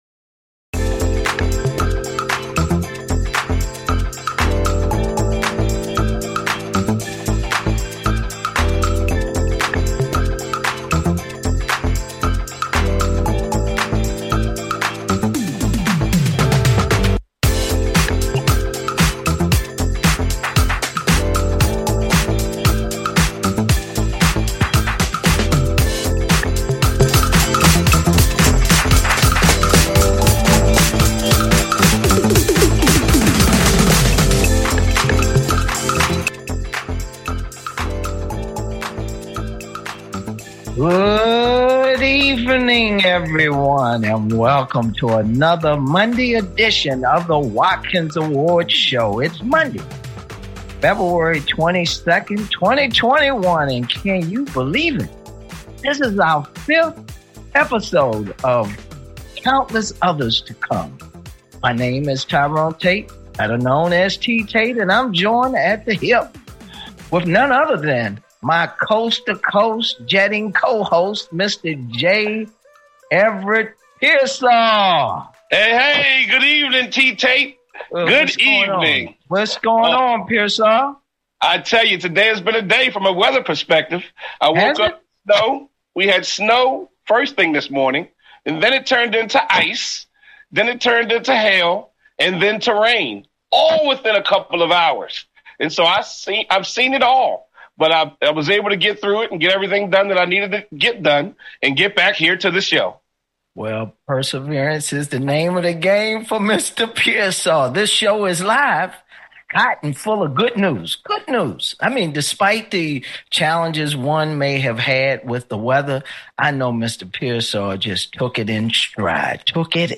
Guest, Lorenzo Alexander, football player for Carolina, Baltimore, Washington, Arizona, Oakland, and finally Buffalo